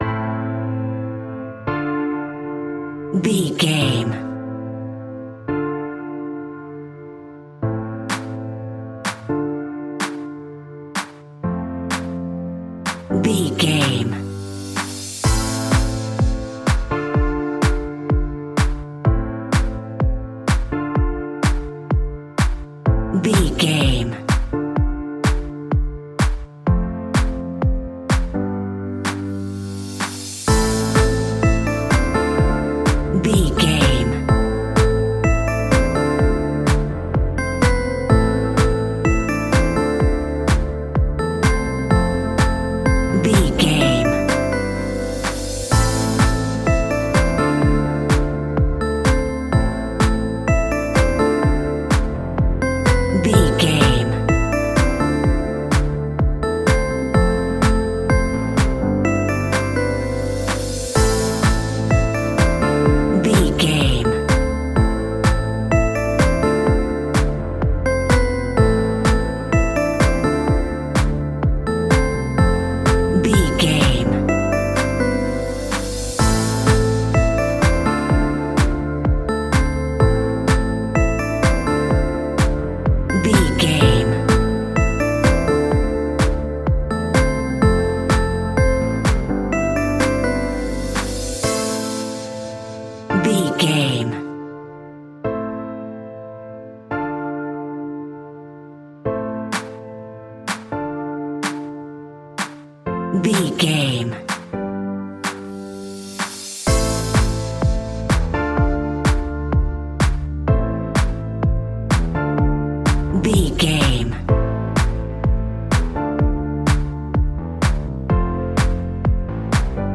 Aeolian/Minor
groovy
uplifting
futuristic
cheerful/happy
drum machine
synthesiser
bass guitar
funky house
deep house
nu disco
upbeat
funky guitar
wah clavinet
synth bass
synth leads